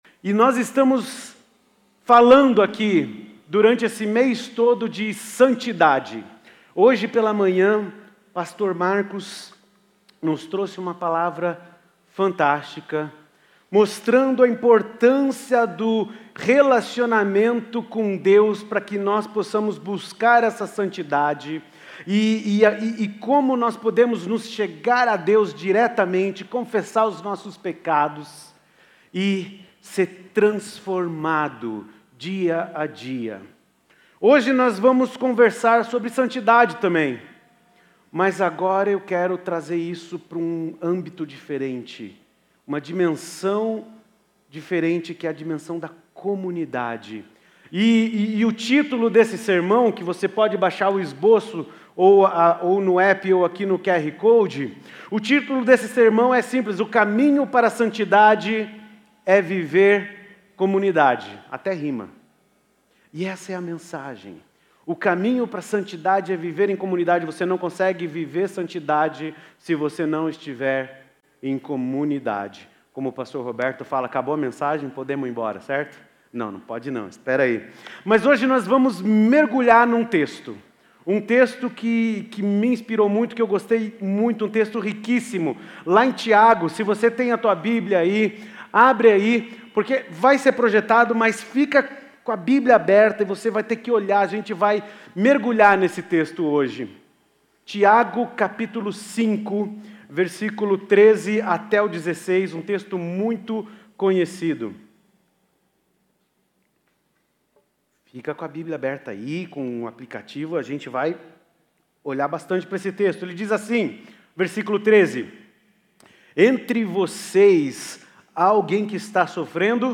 Mensagem apresentada
na Igreja Batista do Bacacheri.